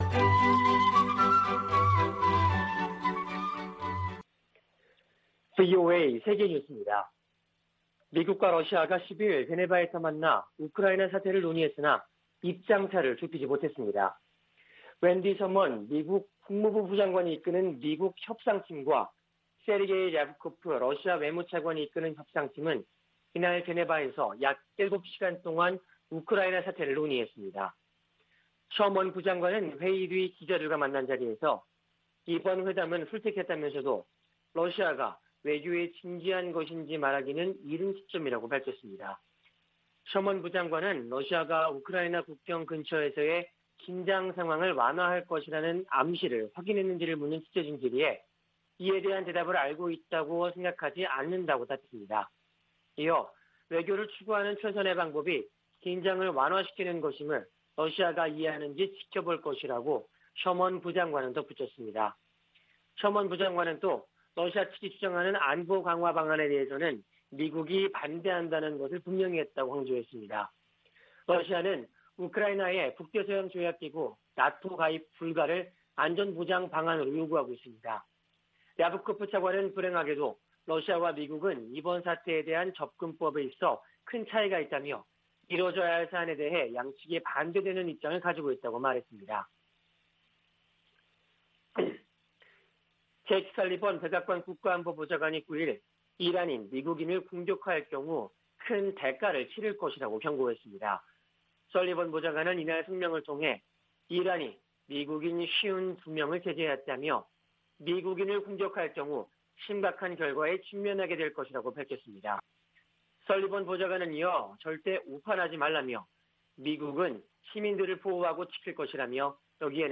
VOA 한국어 아침 뉴스 프로그램 '워싱턴 뉴스 광장' 2021년 1월 11일 방송입니다. 미국 등 5개국이 북한의 미사일 발사에 대한 유엔 안보리 협의를 요청했다고 미 국무부가 밝혔습니다. 북한 극초음속 미사일을 방어하기 위해서는 초기 탐지 능력이 중요하다고 미국의 전문가들은 지적했습니다. 지난해 10월 북한에 들어간 지원 물자가 소독 등을 마치고 정부 배급소에 도착했다고 유엔이 확인했습니다.